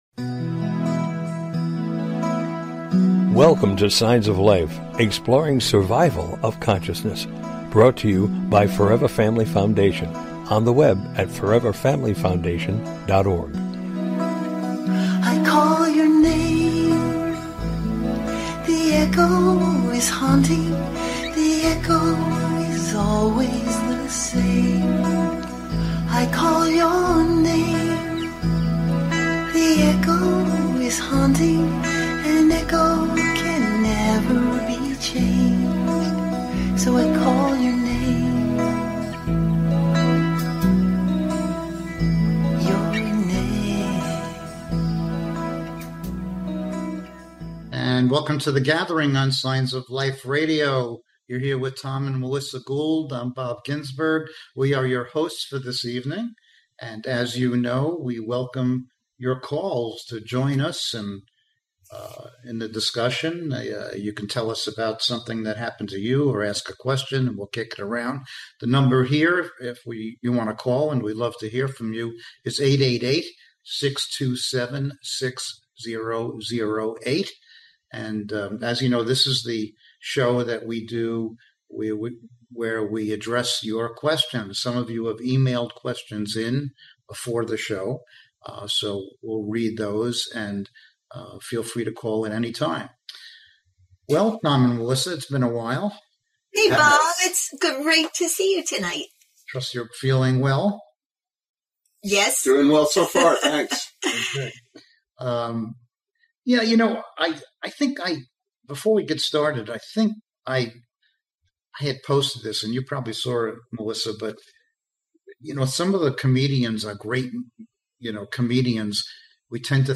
Join us this evening for an engaging live discussion on life after death and consciousness!
Tonight our panel will be addressing questions from our listeners that pertain to all matters related to life after death, consciousness, spirituality, etc.